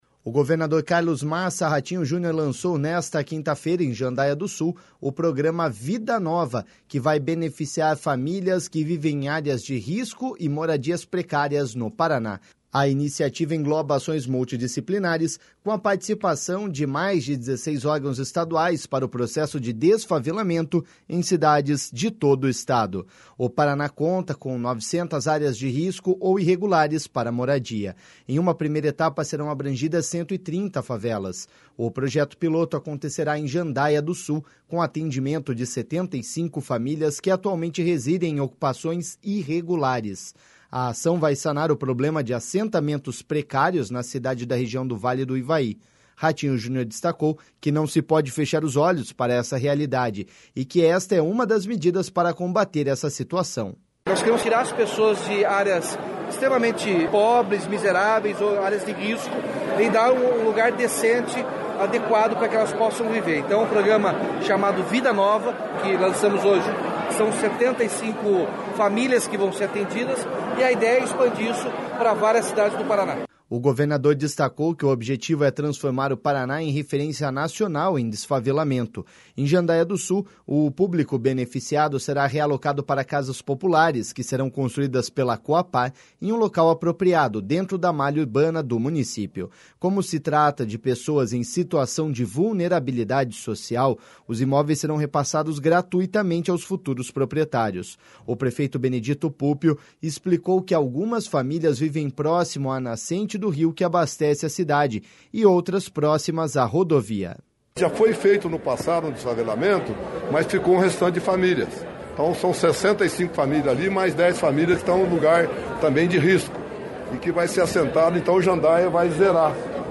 Ratinho Junior destacou que não se pode fechar os olhos para essa realidade, e que esta é uma das medidas para combater esta situação.// SONORA RATINHO JUNIOR.//
O prefeito Benedito Puppio explicou que algumas famílias vivem próximo à nascente do rio que abastece a cidade e outras próximas à rodovia.// SONORA BENEDITO PUPPIO.//
Já para o secretário do Desenvolvimento Urbano e Obras Públicas, João Carlos Ortega, o Vida Nova é uma ação de inclusão social.// SONORA JOÃO CARLOS ORTEGA.//